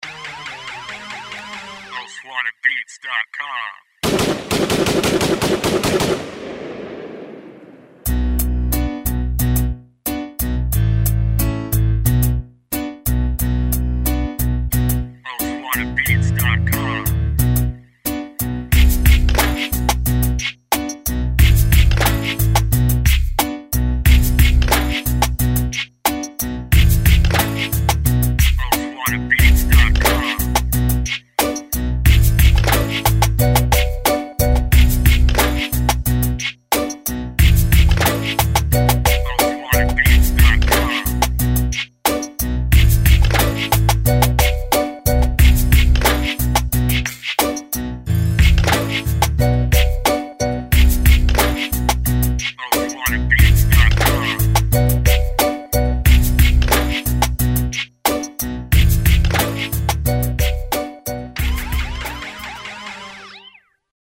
HIP HOP INSTRUMENTAL